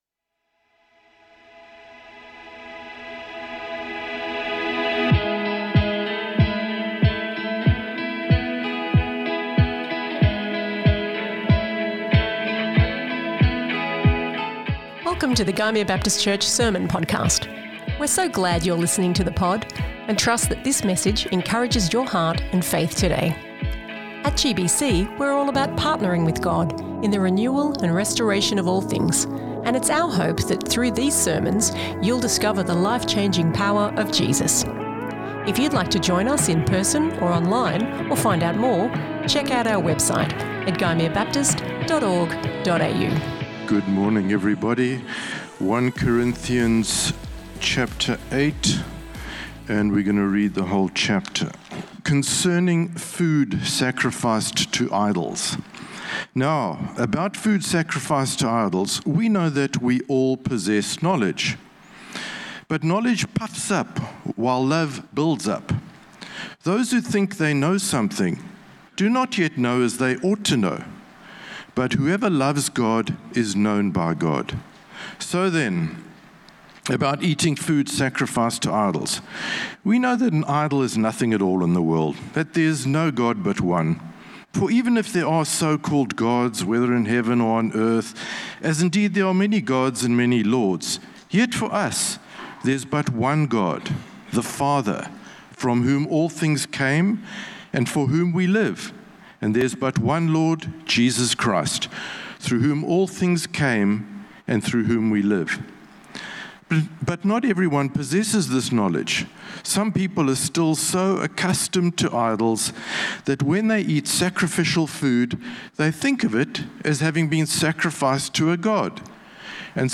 GBC | Sermons | Gymea Baptist Church